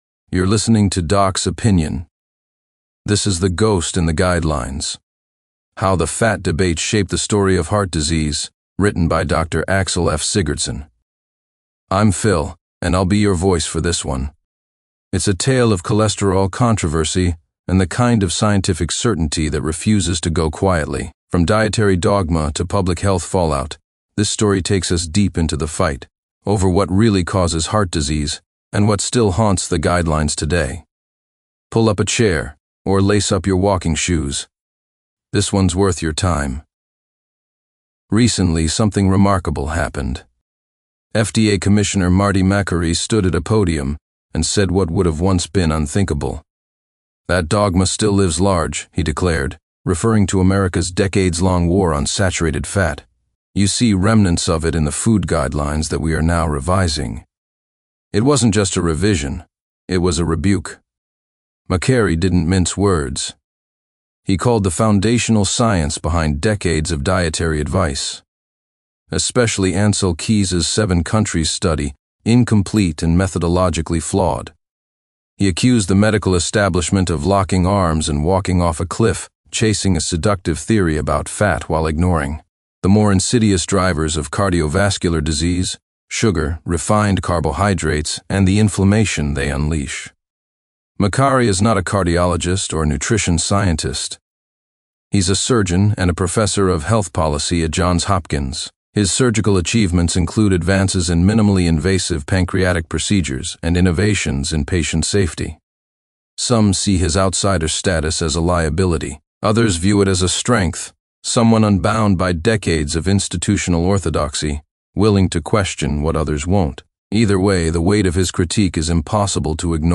🎧 Available in audio You can listen to this article — The Echo Chamber of Cardiology — narrated in full.